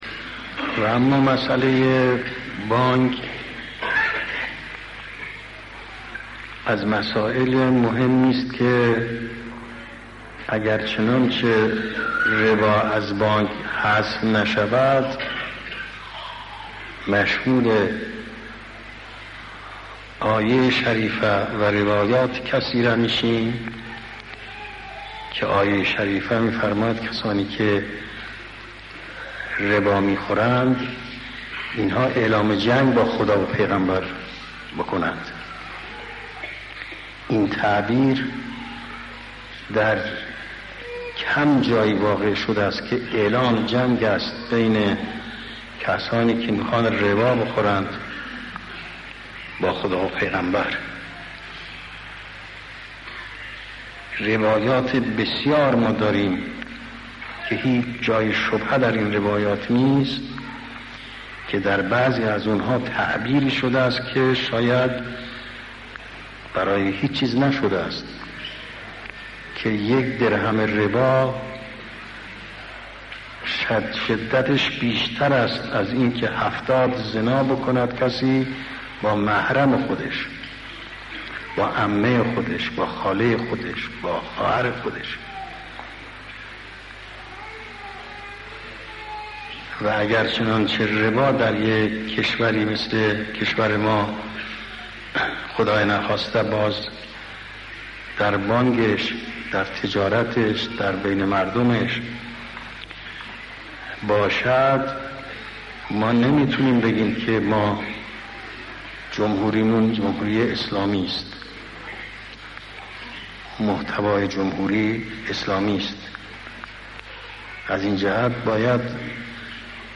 در این گزارش صدای سخنرانی حضرت امام(ره) درباره عواقب سنگین بانکهای ربوی درج شده است.